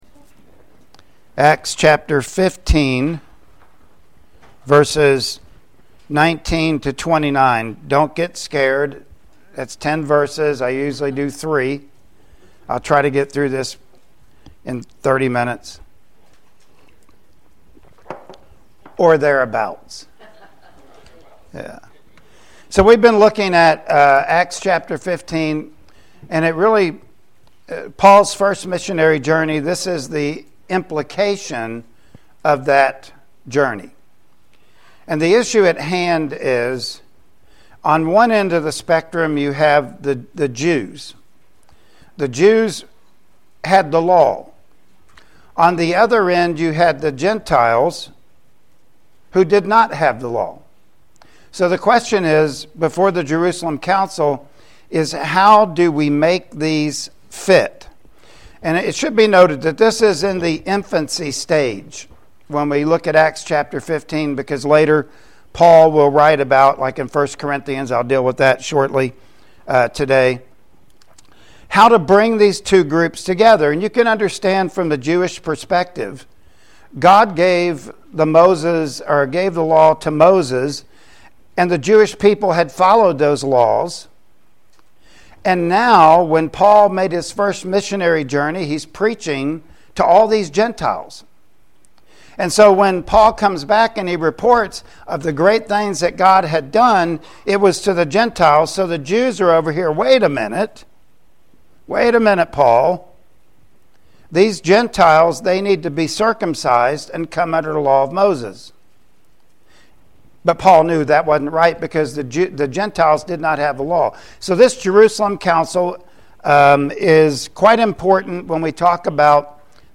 Passage: Acts 15:19-29 Service Type: Sunday Morning Worship Service Topics